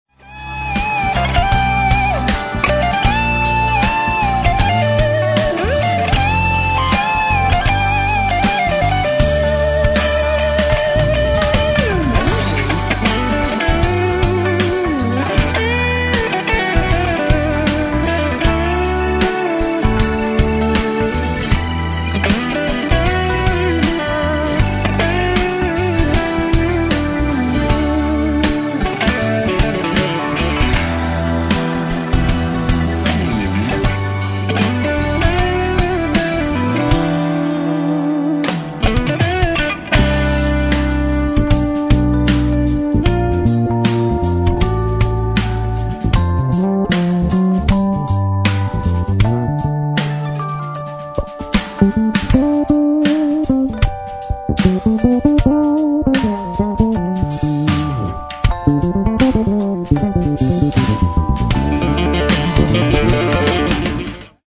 instrumental obrada